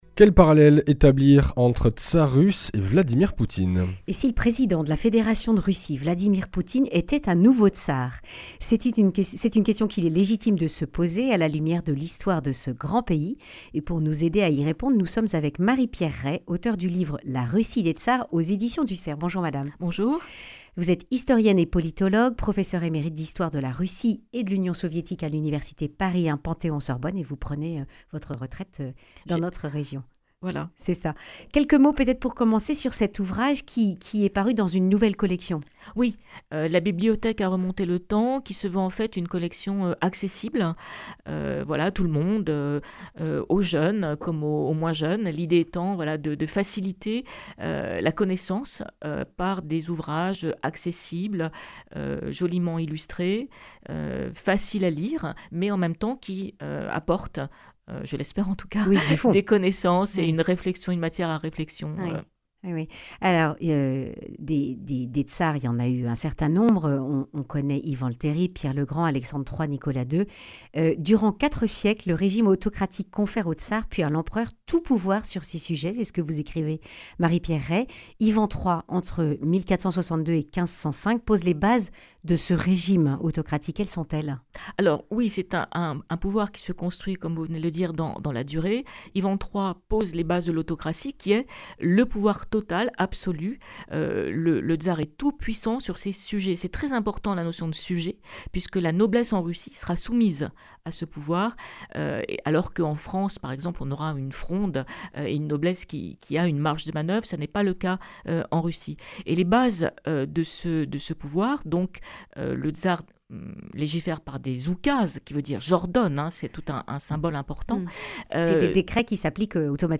Accueil \ Emissions \ Information \ Régionale \ Le grand entretien \ Quels parallèles entre les tsars russes et Vladimir Poutine ?